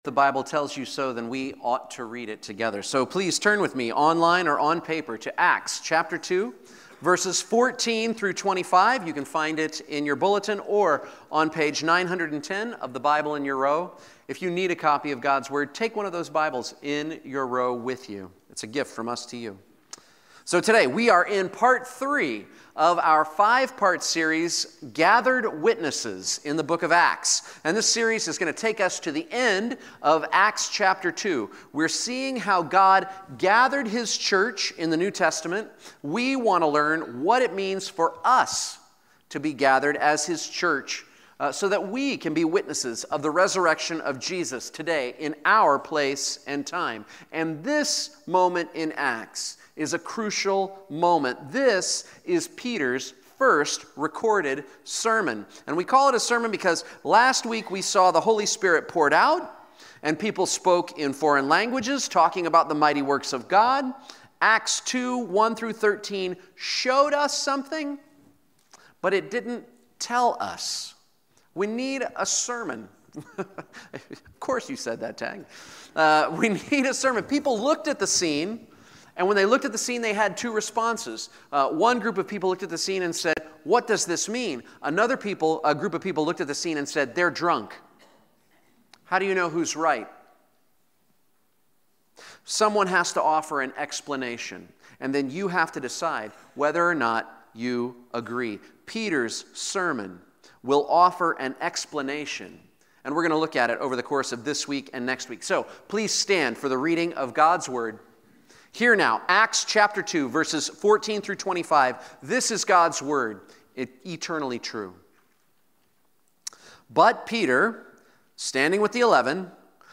Proclaiming the Kingdom of God | Acts 2:14-25 | Valley Springs Presbyterian Church
sermon